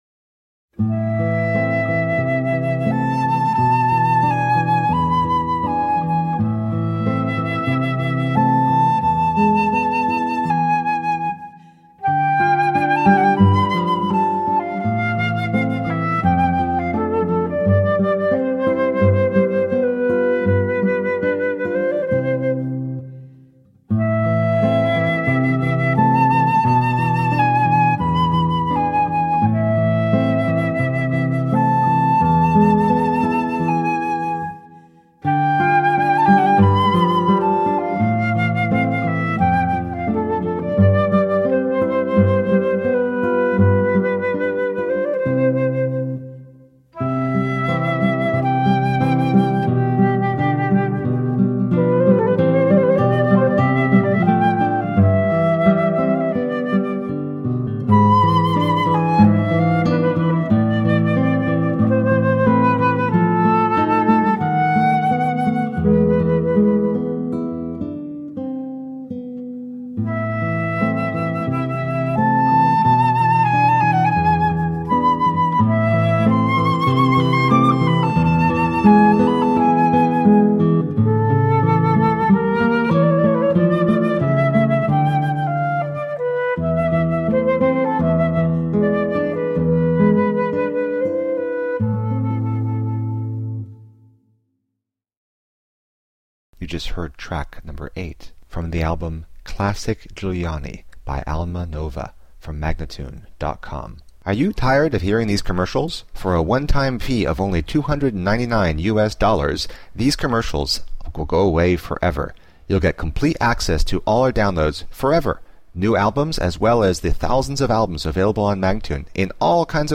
Lively flute/guitar duo.